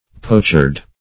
Poachard \Poach"ard\ (p[=o]ch"[~e]rd), n. [From Poach to